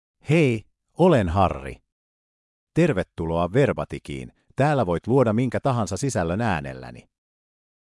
Harri — Male Finnish AI voice
Harri is a male AI voice for Finnish (Finland).
Voice sample
Listen to Harri's male Finnish voice.
Male
Harri delivers clear pronunciation with authentic Finland Finnish intonation, making your content sound professionally produced.